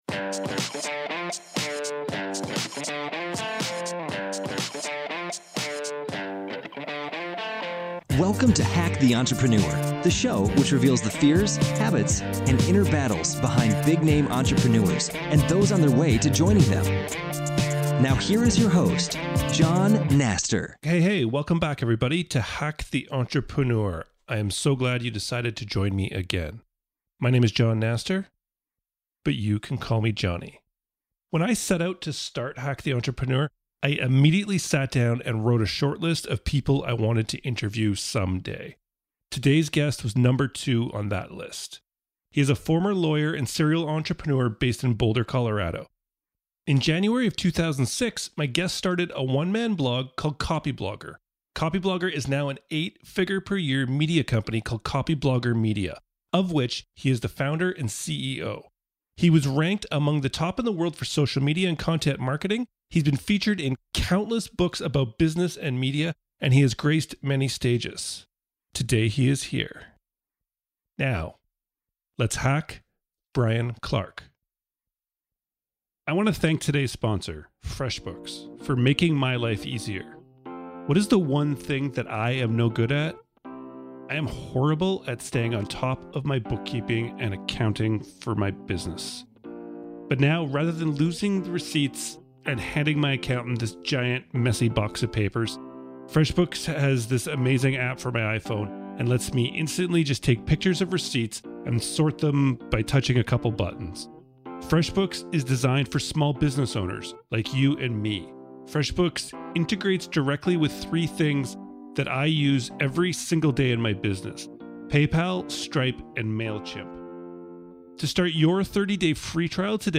I used this list as one of my metrics for success and I am happy to say that today’s guest is number two on that list.